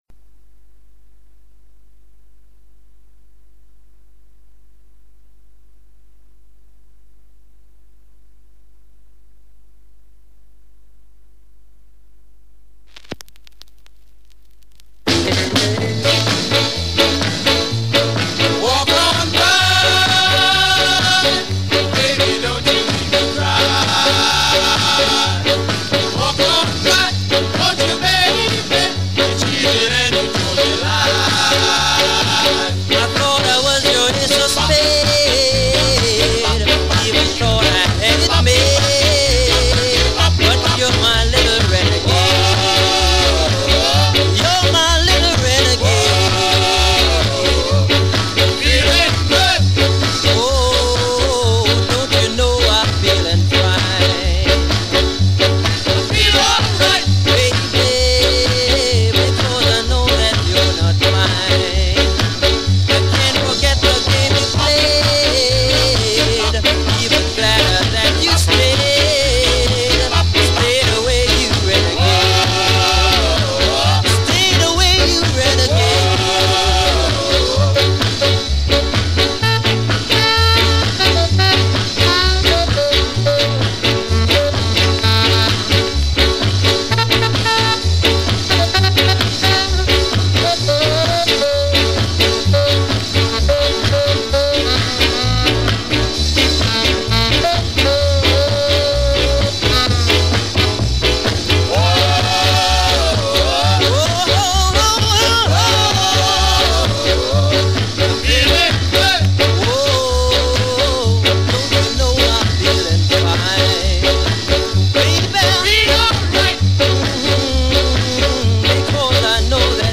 Strictly vinyls and original press